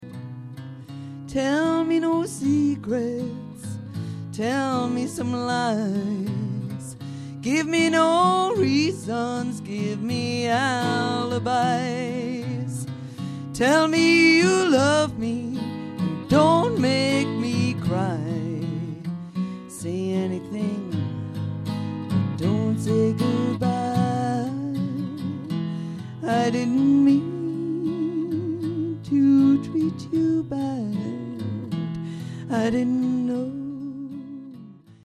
Ashington Folk Club - Singers, Musicians & Poets 01 June 2006